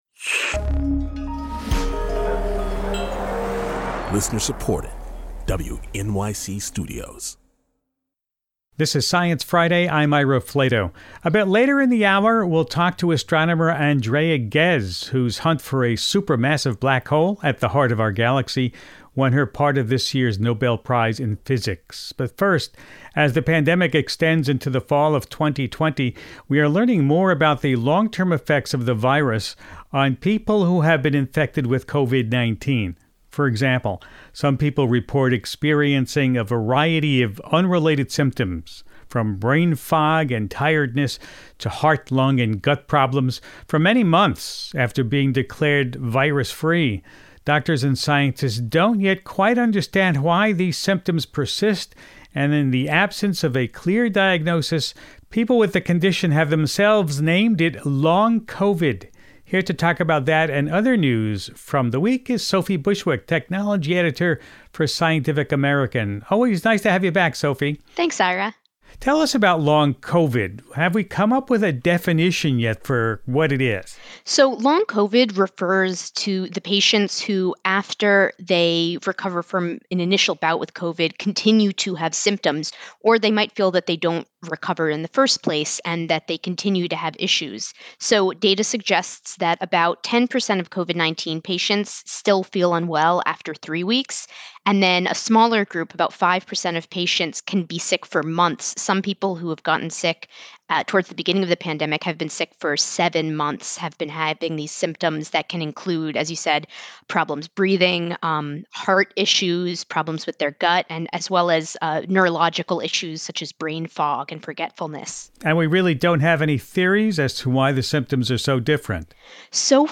Ira talks to Ghez about how our understanding of the center of the galaxy has evolved, plus the questions that still puzzle her.